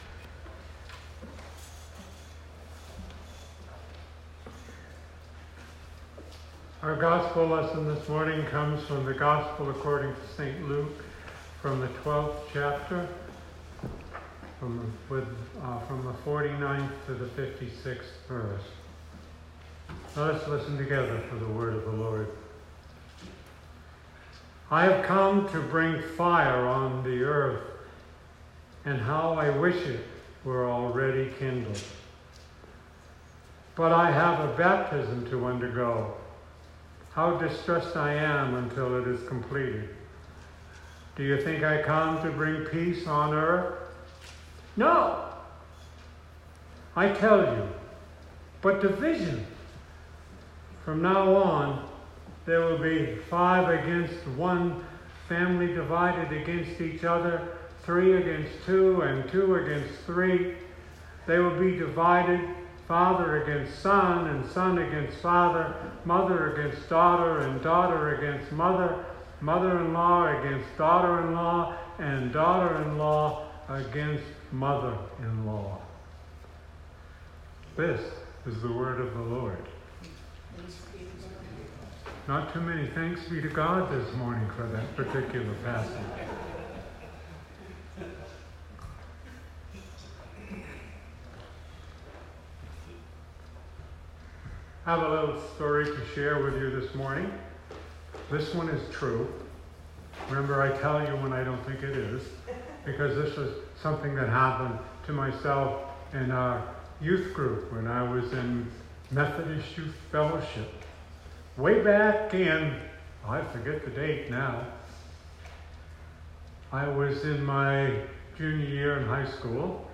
Sermon 2019-08-18